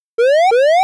Normalize all wav files to the same volume level.
whoopsound.wav